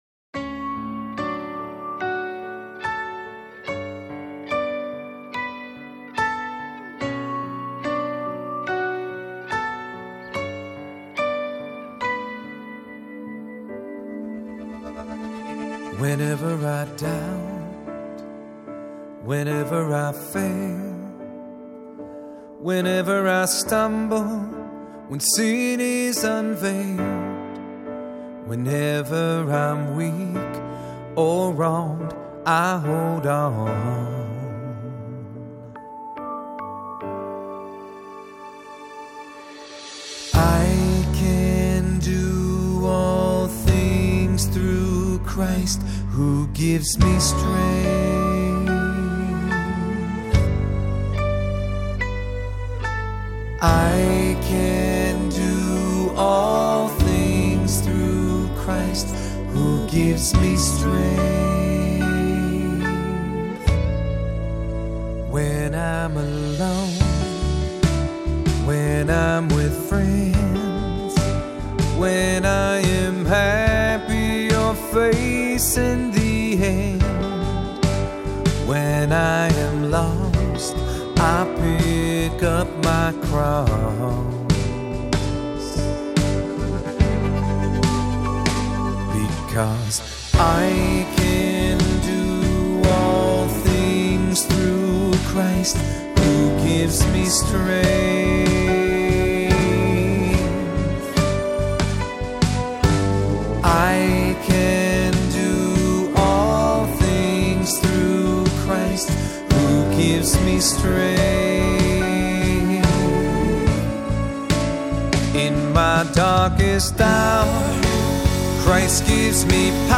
Voicing: Assembly,3-part Choir, cantor